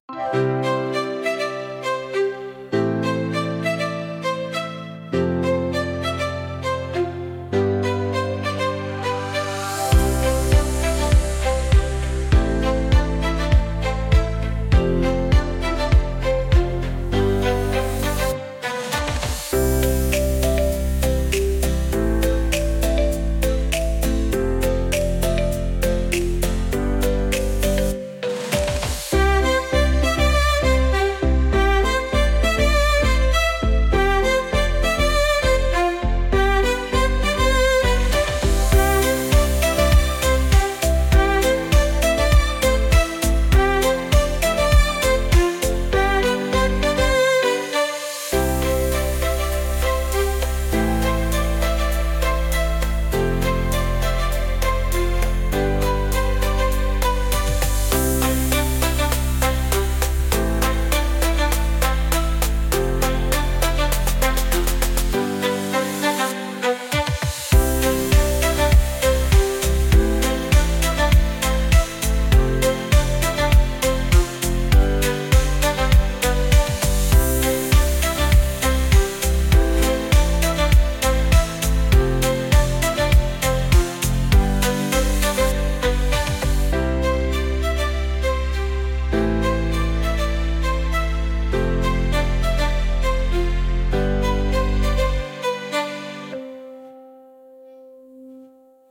Wesoły podkład muzyczny ze skrzypcami